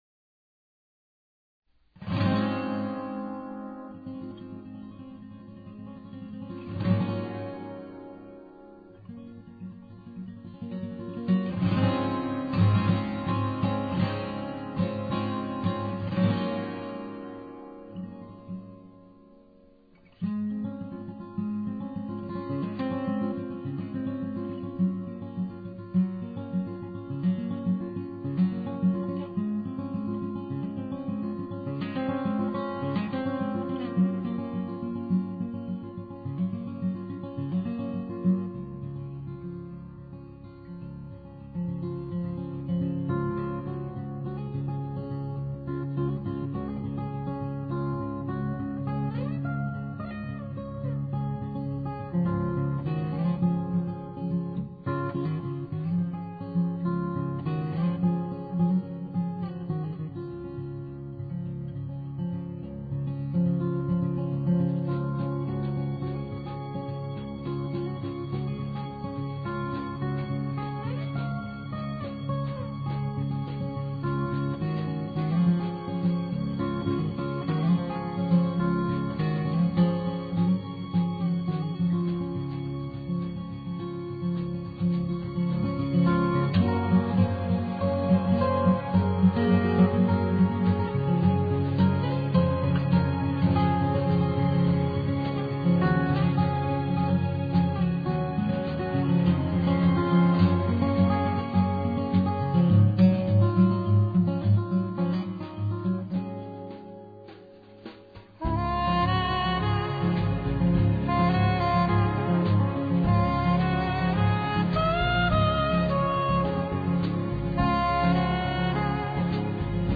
soprano saxophone